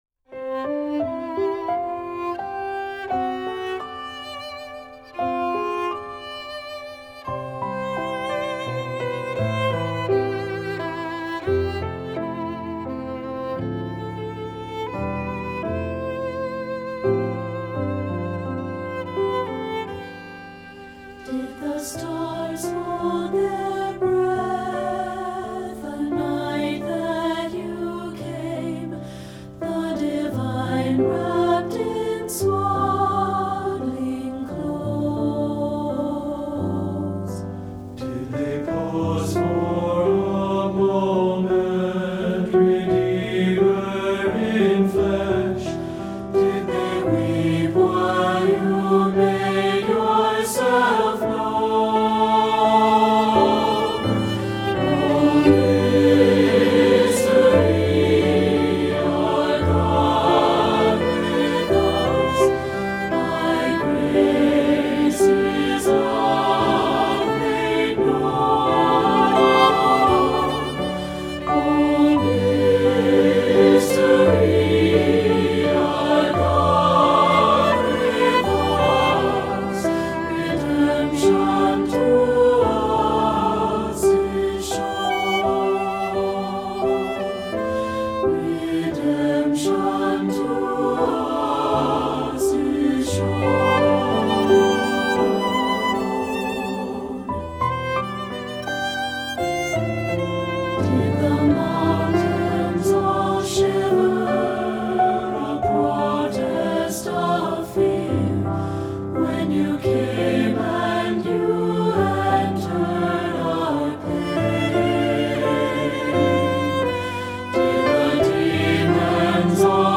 Voicing: SATB and Piano